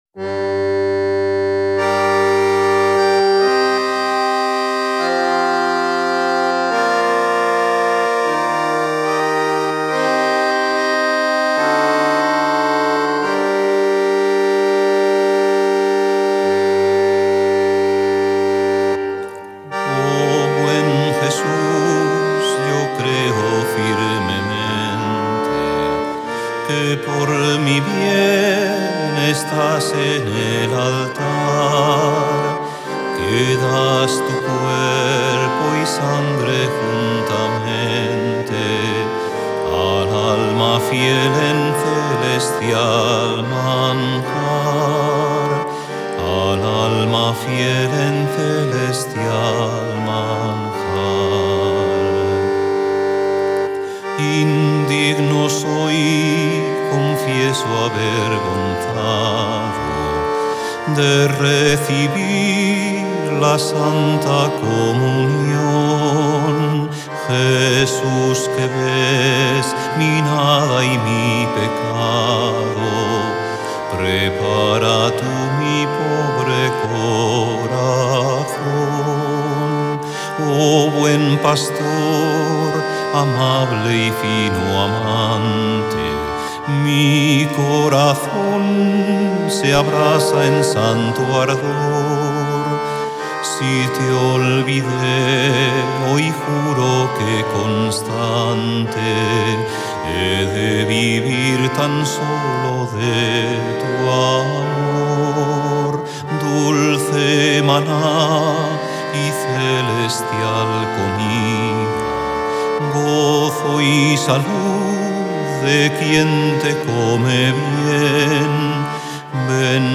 Órgano